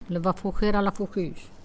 Langue Maraîchin
Catégorie Locution